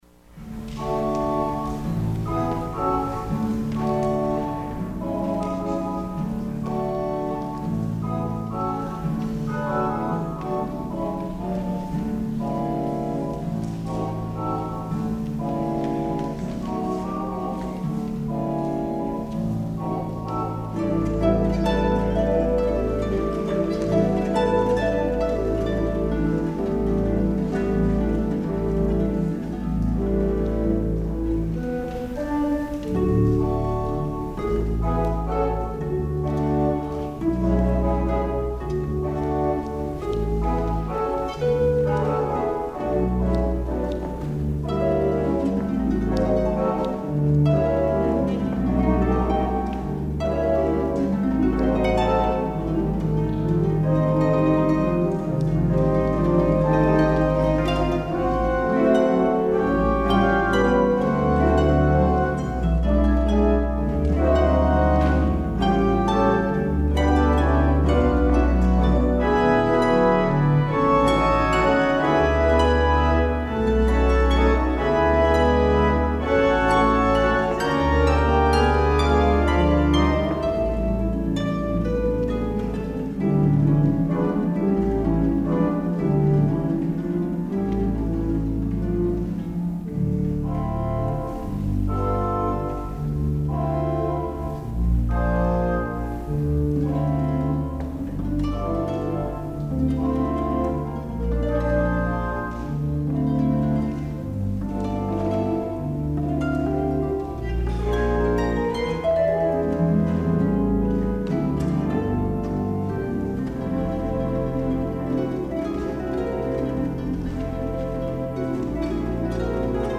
harp
organ